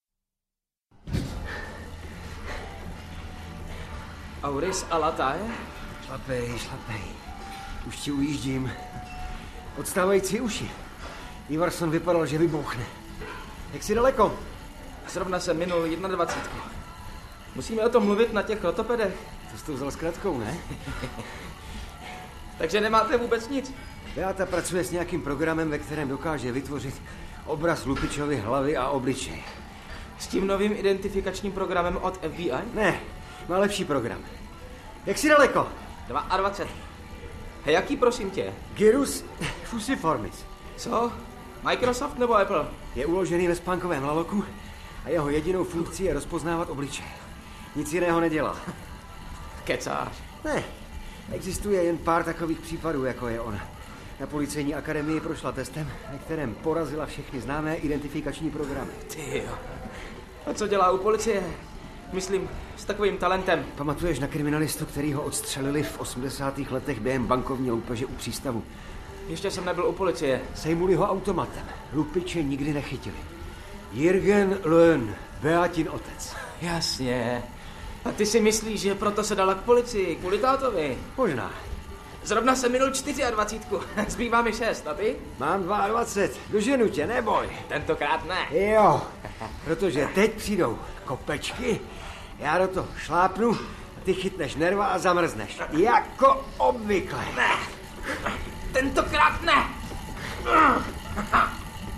Nemesis audiokniha
Ukázka z knihy
Příběh dobrý, zkrácená verze je prostě zkrácená, ale tolik rušivých doprovodných zvuků, co tam je, to je hrůza.
Ale Nemesis je nejslabší díky zpracování do rozhlasové hry.